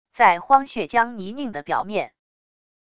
在荒血浆泥泞的表面wav音频